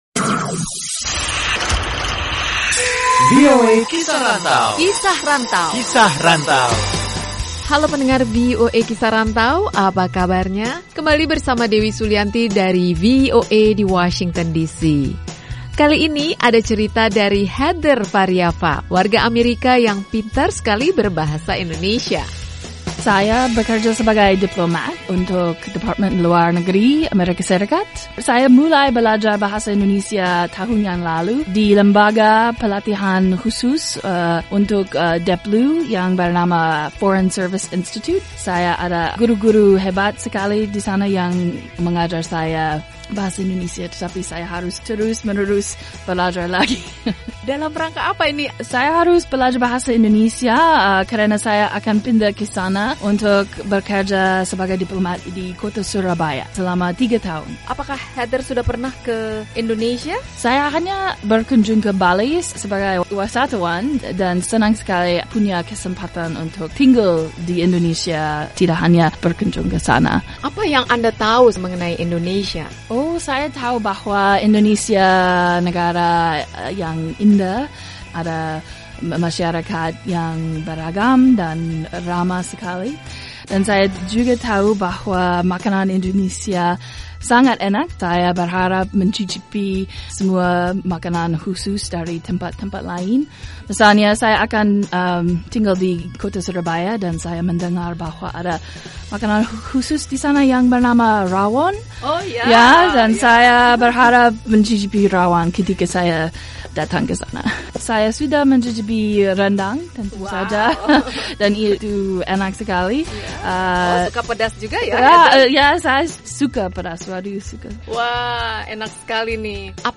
Kali ini ada cerita dari Heather Variava yang tidak sabar lagi pindah ke Surabaya untuk tugas dan mencicipi masakan Indonesia. Simak obrolan Heather